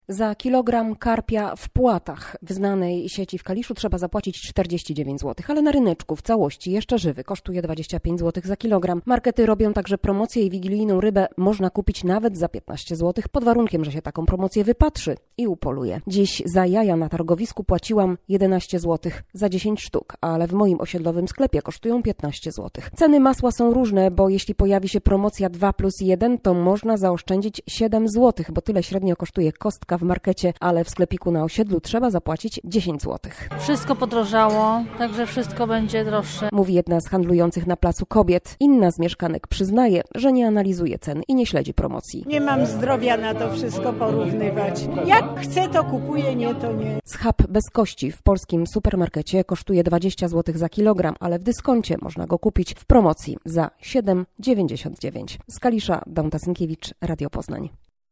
"Wszystko podrożało, także święta będą droższe"- mówi jedna z handlujących na placu kobiet.